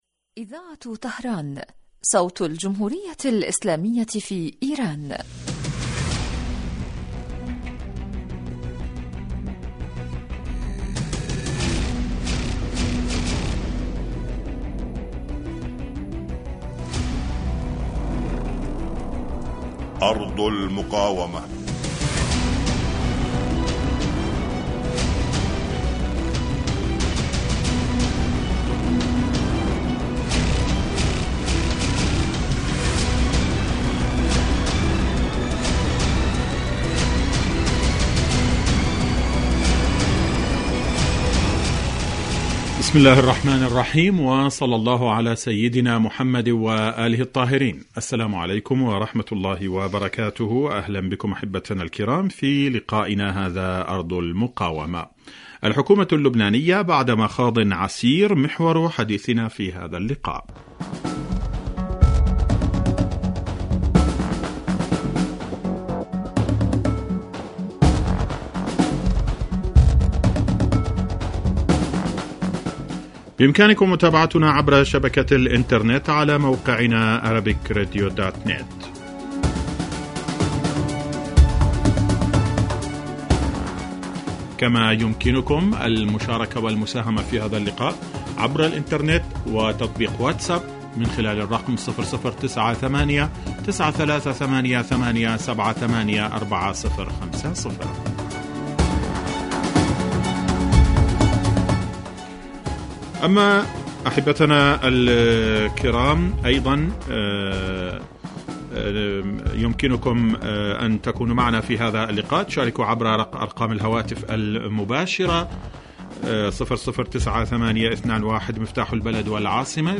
برنامج إذاعي حي لنصف ساعة يتناول بالدراسة والتحليل آخر التطورات والمستجدات على صعيد سوريا والأردن وفلسطين المحتلة ولبنا
يستهل المقدم البرنامج بمقدمة يعرض فيها أهم ملف الأسبوع ثم يوجه تساؤلاته إلى الخبراء السياسيين الملمين بشؤون وقضايا تلك الدول والذين تتم استضافتهم عبر الهاتف